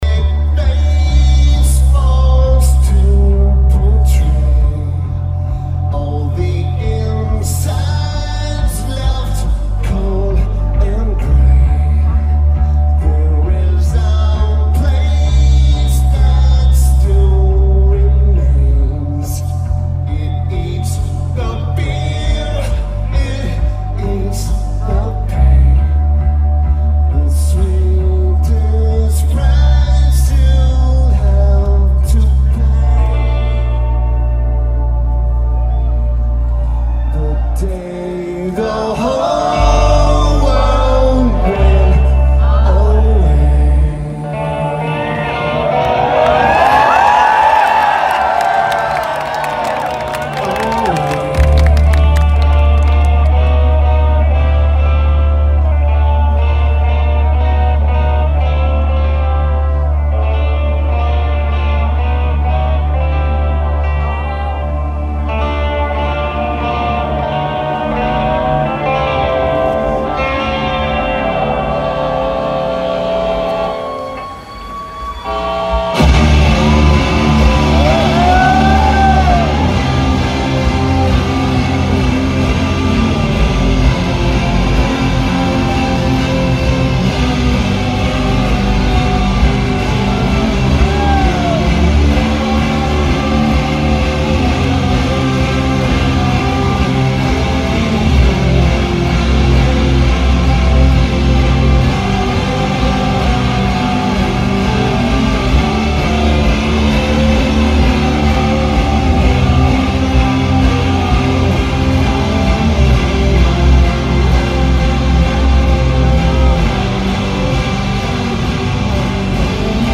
Lineage: Audio - AUD, Ripped from Video Recording